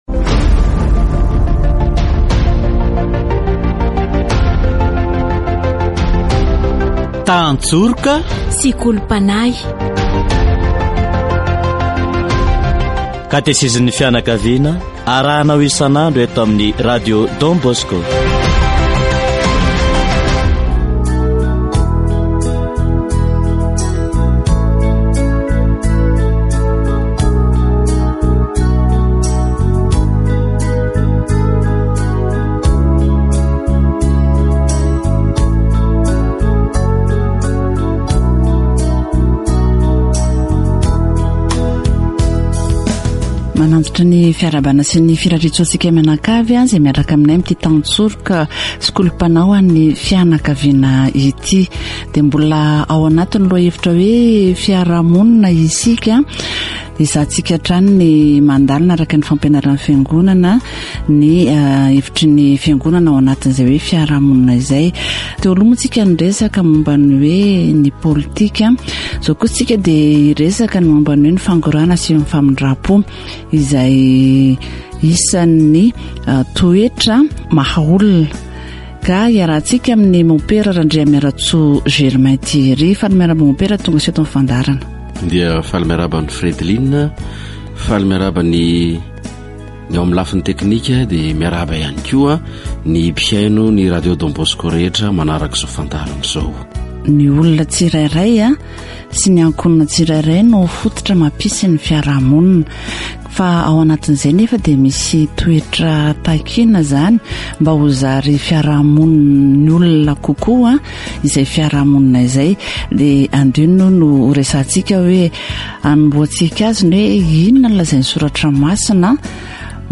Catéchèse sur la Société plus humaine